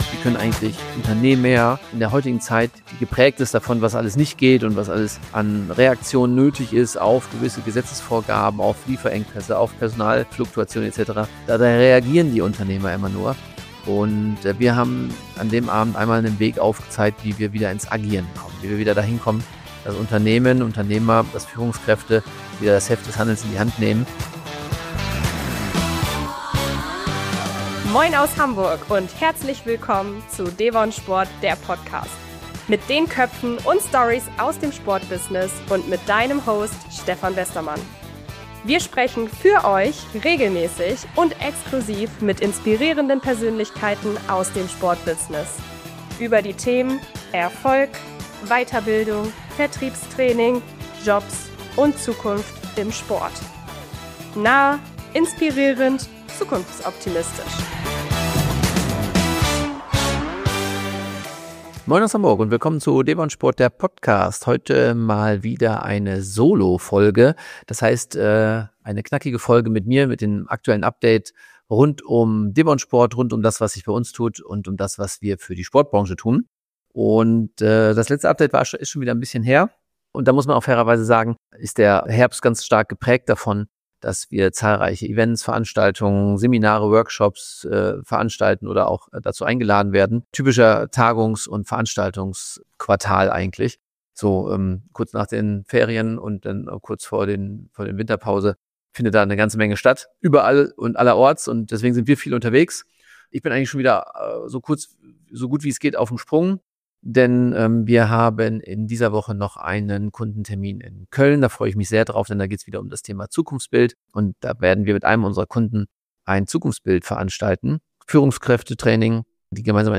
Freue dich in dieser Solo-Folge ein Update über die neuesten Entwicklungen: Von Zukunftsbild-Wor...